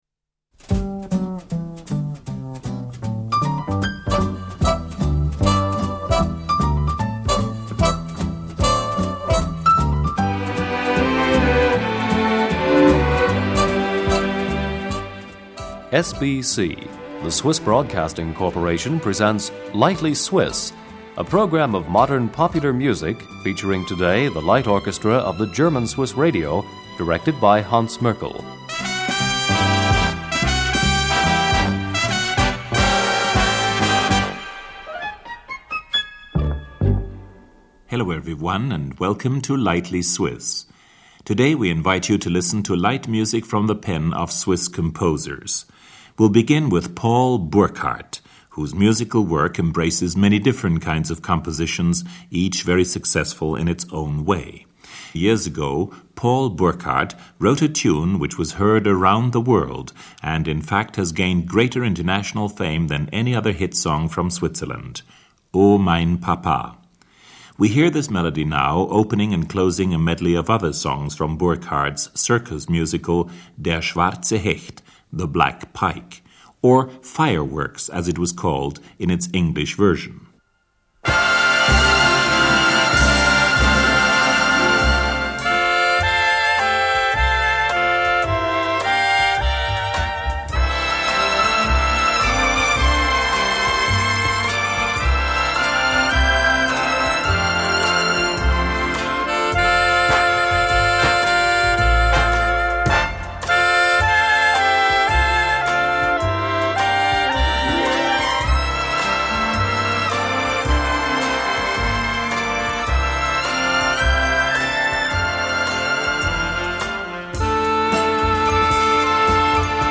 (musical comedy)